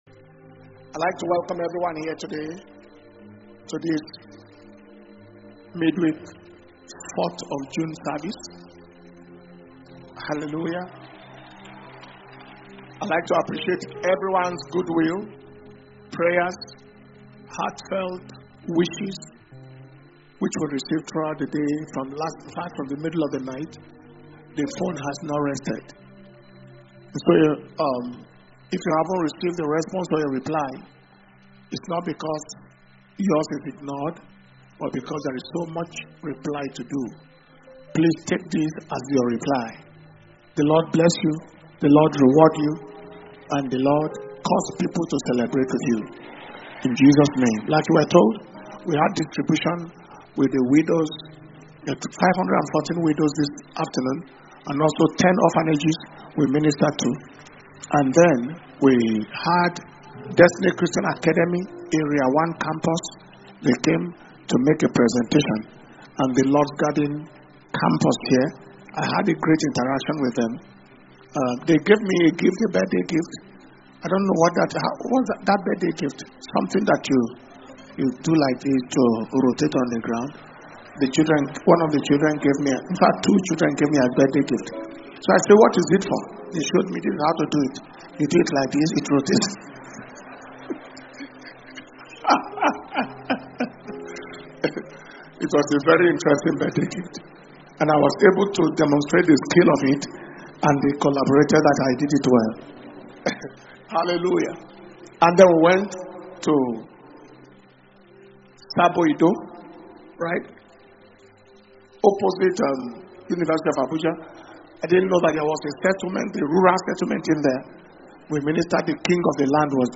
June 2025 Preservation And Power Communion Service